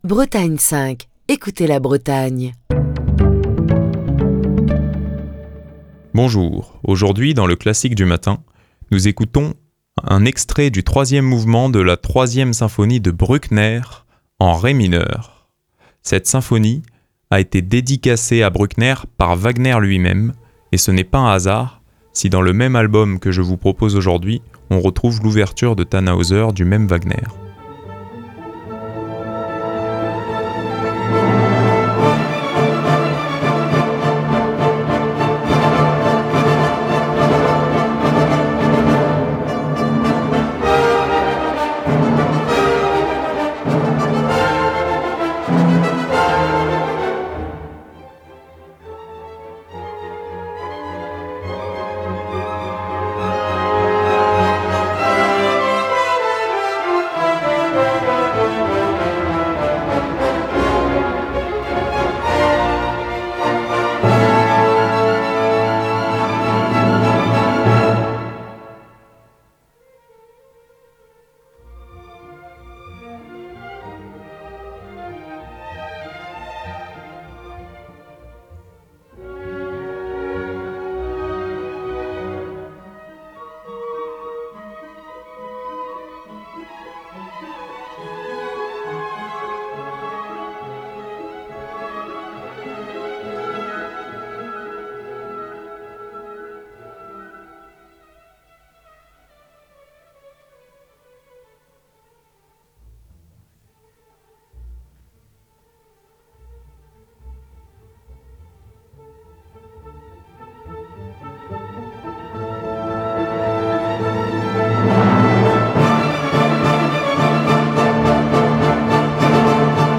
en Ré mineur